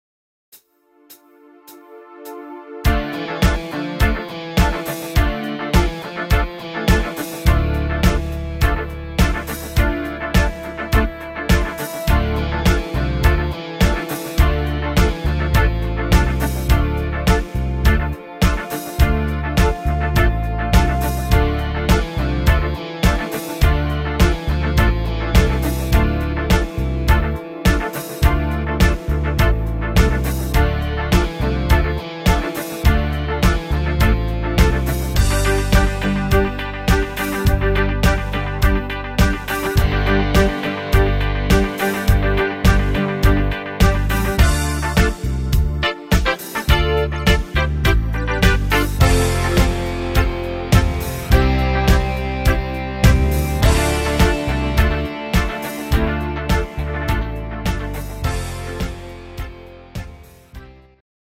Playback abmischen  Playbacks selbst abmischen!
Rhythmus  Rock
Art  Oldies, Rock, Englisch, Mega Hits